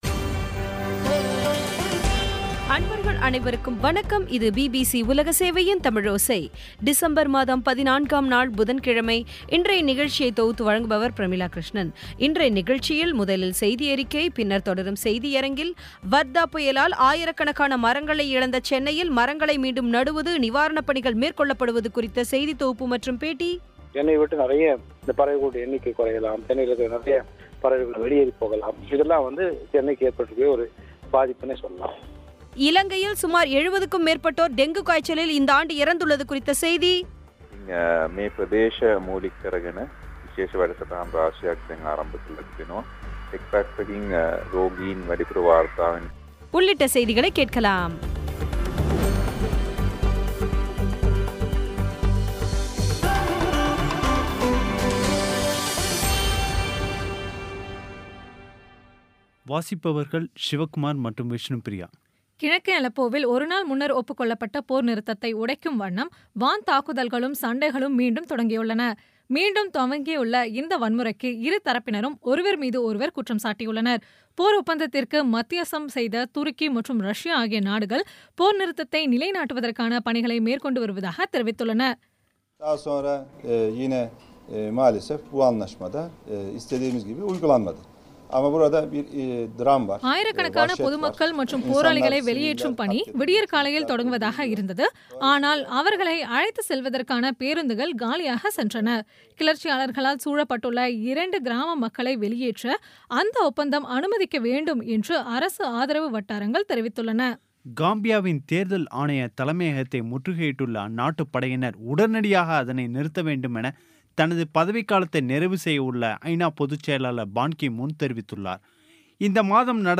இன்றைய நிகழ்ச்சியில் முதலில் செய்தியறிக்கை, பின்னர் தொடரும் செய்தியரங்கில் ஆயிரக்கணக்கான மரங்களை இழந்த சென்னையில் மரங்களை மீண்டும் நடும் நிகழ்வுகள் குறித்த செய்தி தொகுப்பு மற்றும் பேட்டி இலங்கையில் சுமார் 70க்கும் மேற்பட்டோர் டெங்கு காய்ச்சலில் இறந்துள்ளது குறித்த செய்தி உள்ளிட்ட செய்திகளை கேட்கலாம்